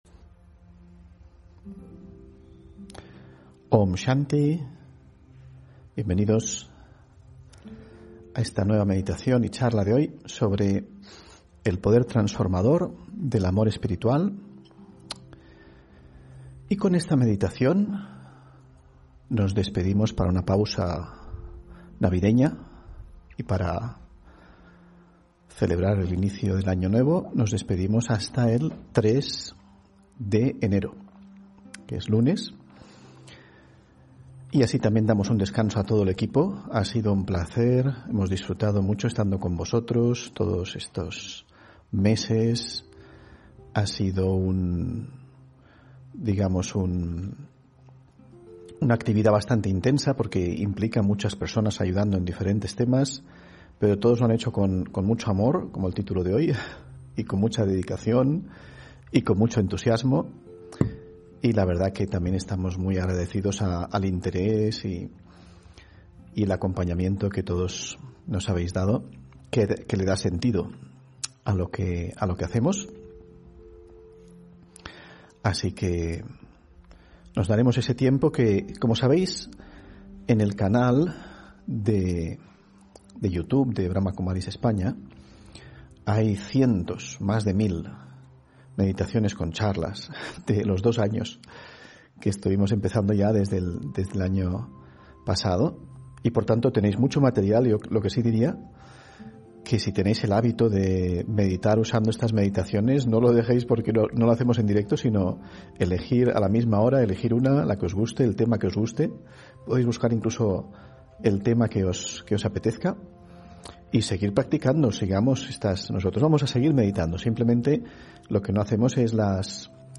Meditación y conferencia: El poder transformador del amor espiritual (21 Diciembre 2021)